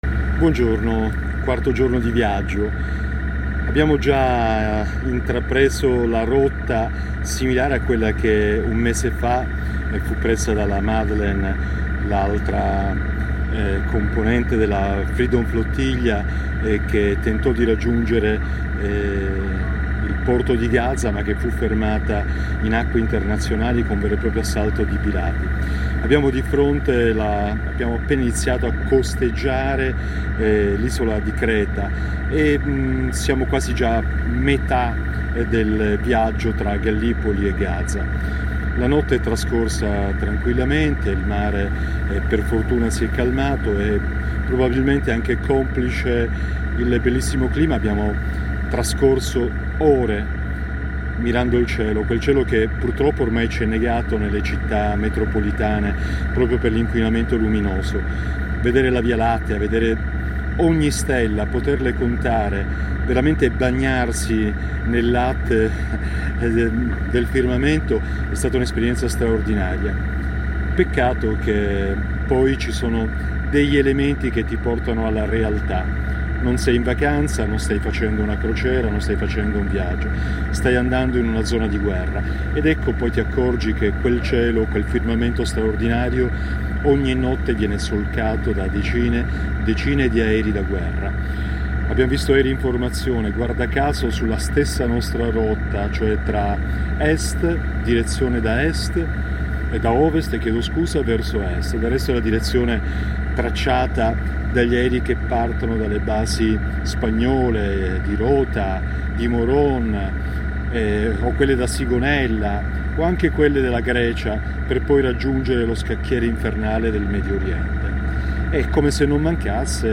a bordo dell’Handala